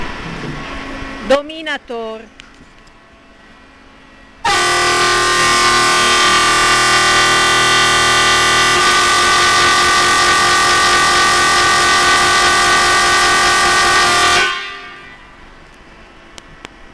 Fisa avertisseur Dominator FN2C 4-8,5 bar110dB395Hz high tone360Hz low tone